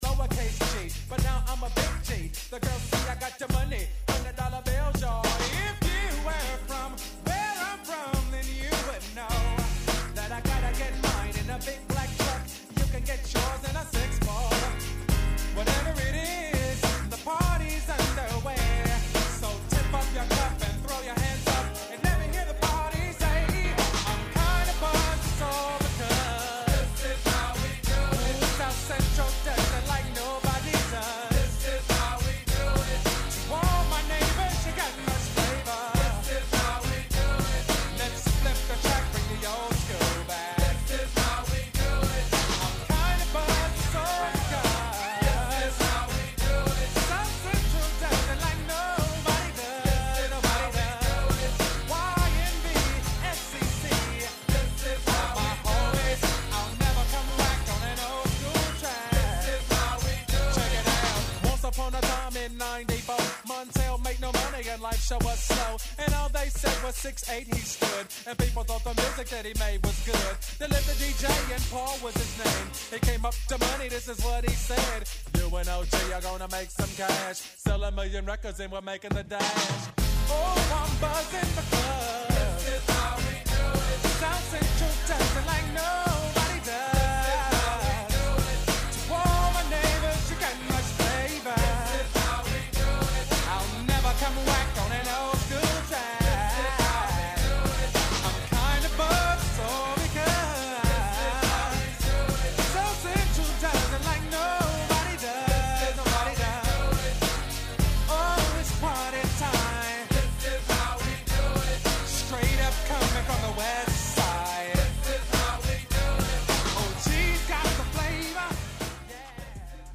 On this show, you’ll hear the recent news, personal experiences and a diverse selection of music.